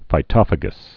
(fī-tŏfə-gəs)